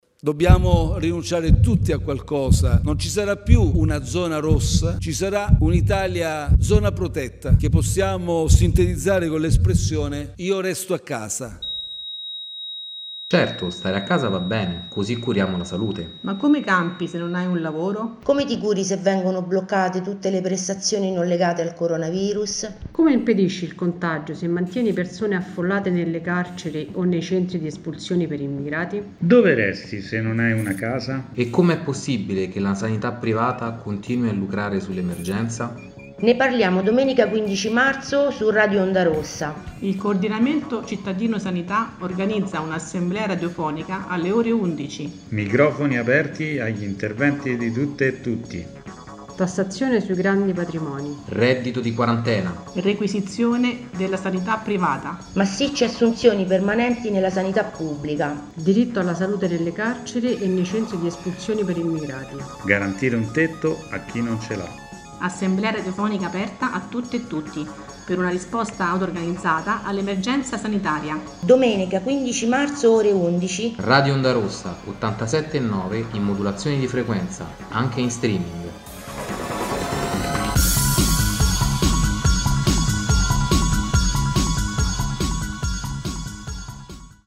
Spottino